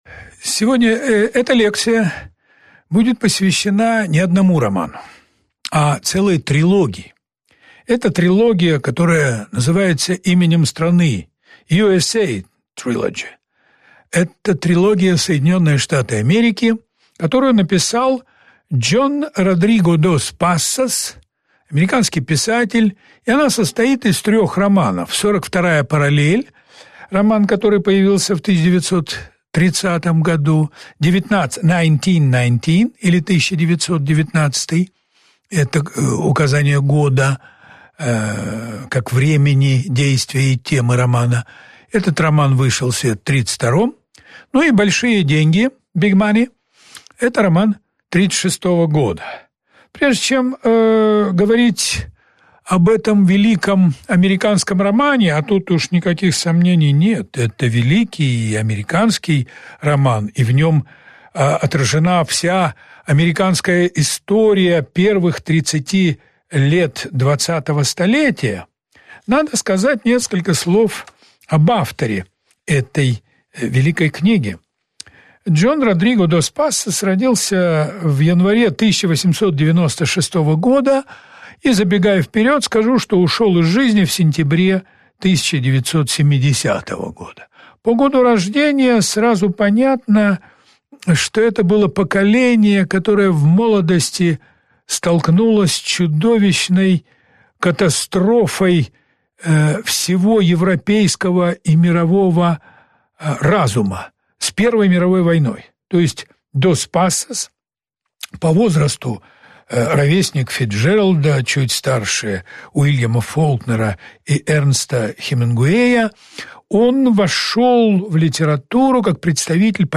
В этой лекции речь пойдет о трилогии Джона Дос Пассоса «США» (1930-1936). Она представляет собой масштабную картину американской жизни начала XX века.